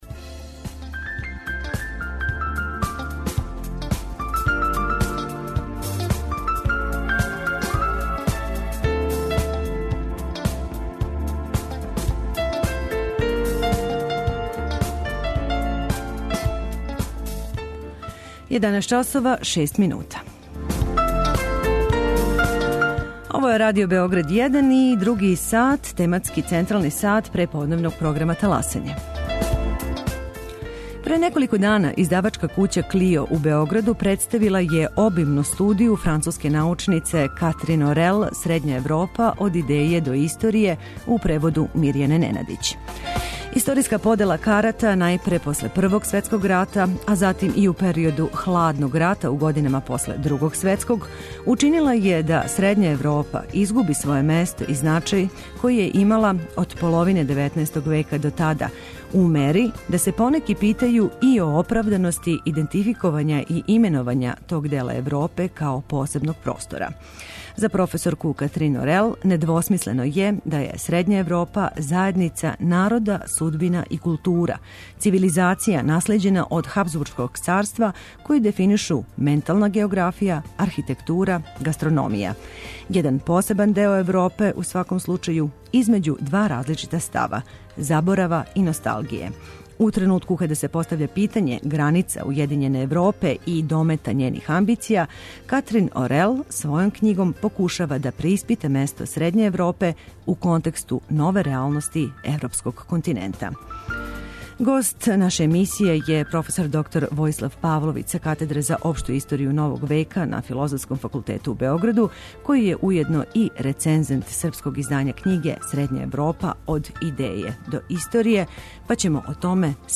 Радио Београд 1, 11.05